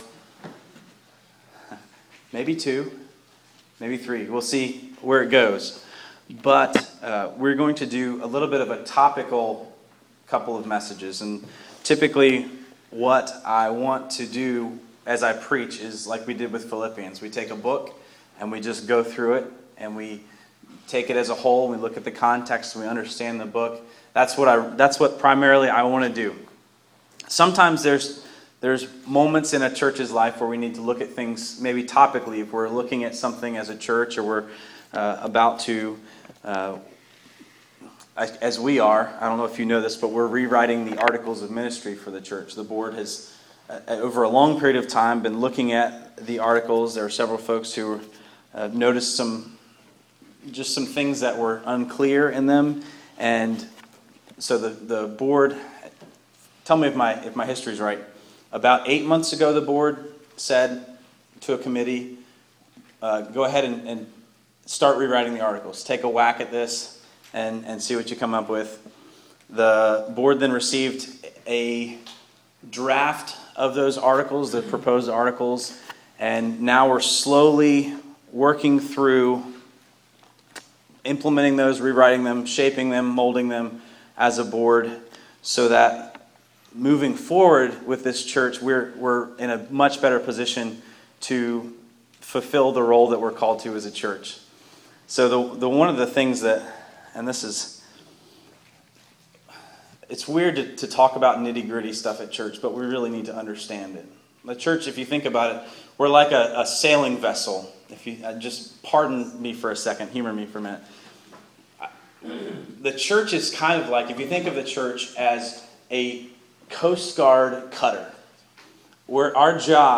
Yes, this is a topical sermon. typically what I like to do is choose a book of the bible and preach right through it.